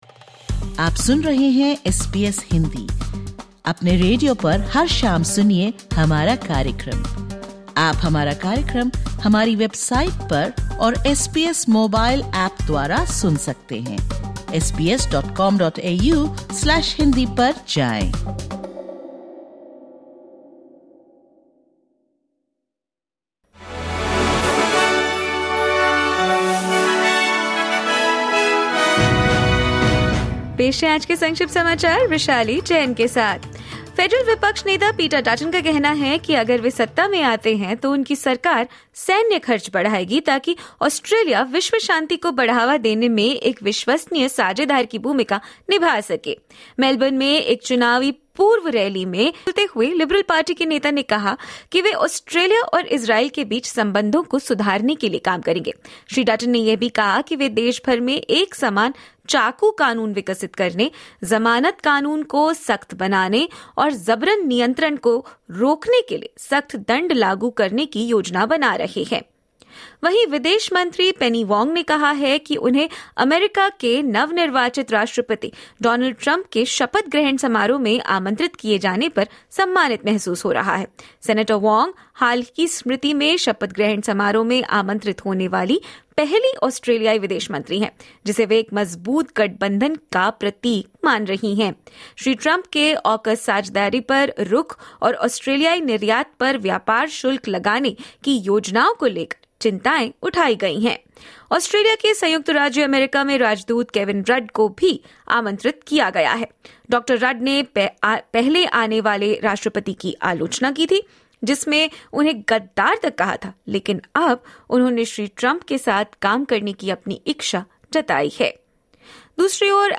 सुनें ऑस्ट्रेलिया और भारत से 12/01/2025 की प्रमुख खबरें।